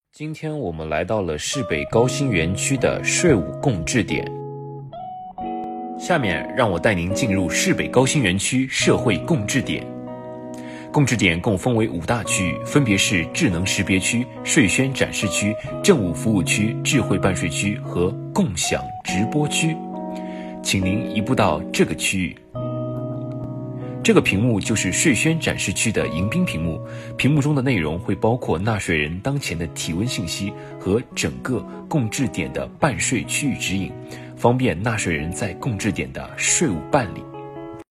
智能识别区域配备的智能识别设备会自动检测入厅人员并测量体温，通过全息屏幕拟人播报迎宾语和大厅区域指引。